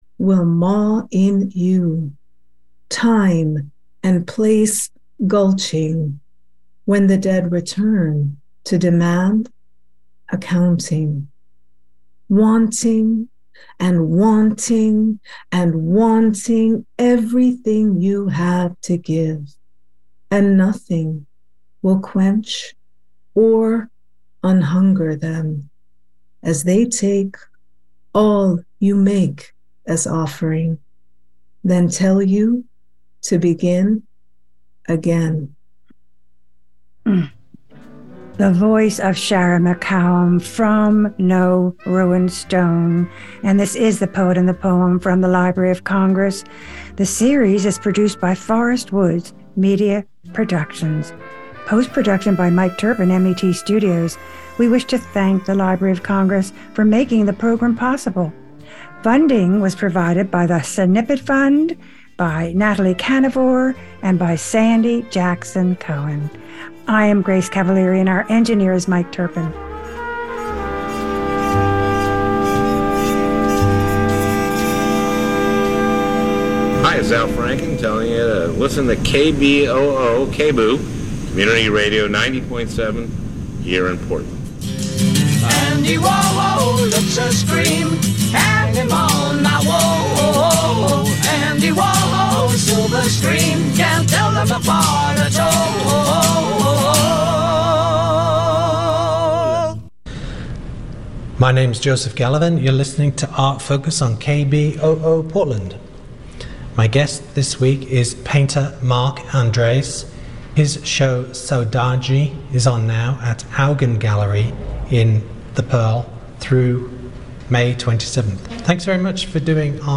KBOO Subscribe to podcast A radio show about visual art.
Lively voices talking about art, how it's made and sometimes even what it means.